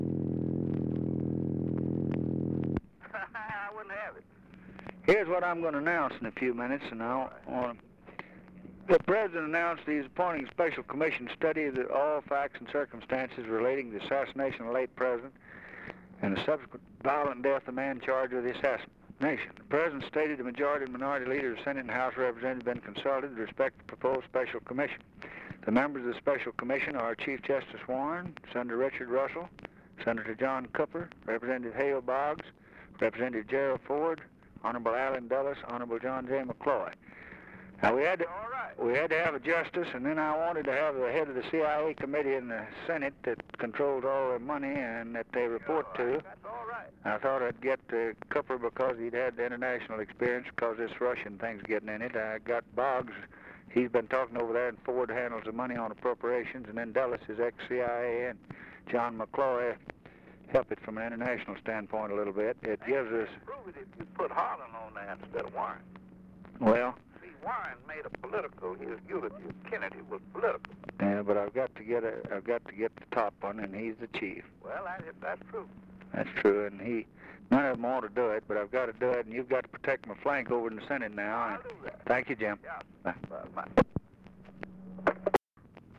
Conversation with JAMES EASTLAND, November 30, 1963
Secret White House Tapes | Lyndon B. Johnson Presidency Conversation with JAMES EASTLAND, November 30, 1963 Rewind 10 seconds Play/Pause Fast-forward 10 seconds 0:00 Download audio Previous Conversation with WILLIAM MCC.